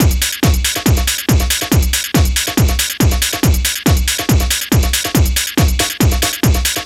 NRG 4 On The Floor 014.wav